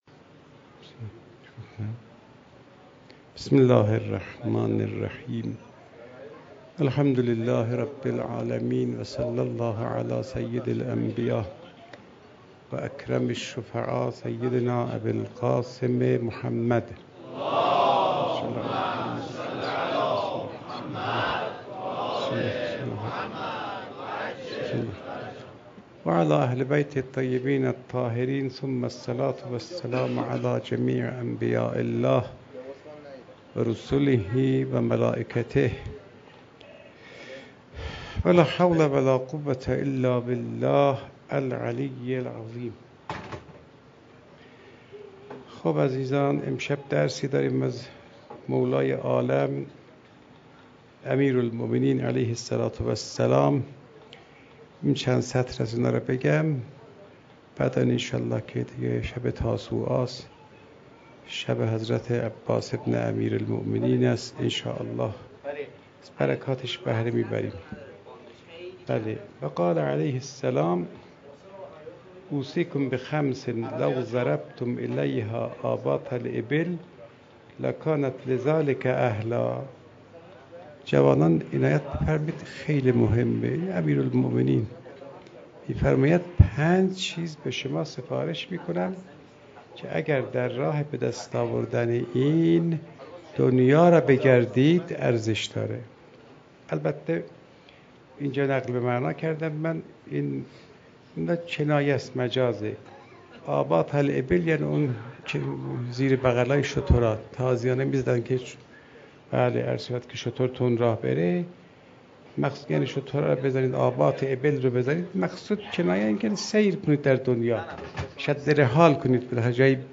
شب هشتم محرم95_سخنرانی